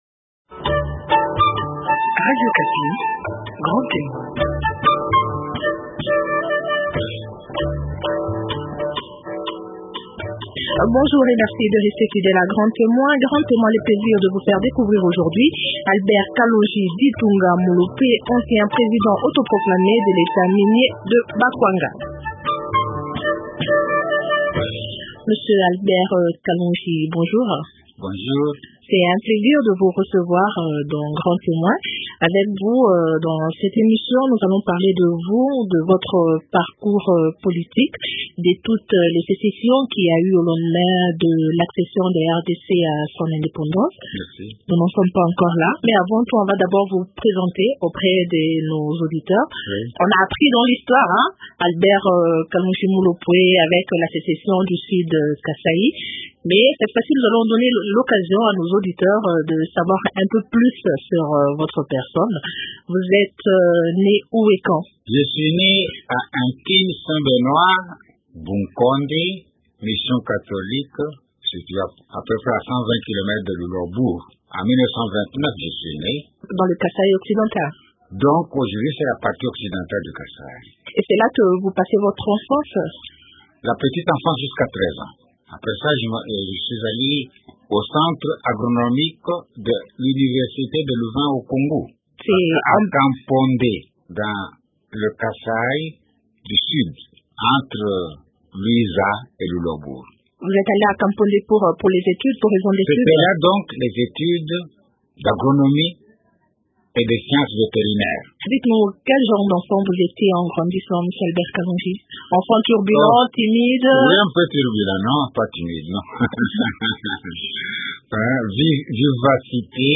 Grand témoin reçoit Albert Kalonji Mulopwe, ancien président autoproclamé de l’Etat Minier de Bakwanga en août 1960.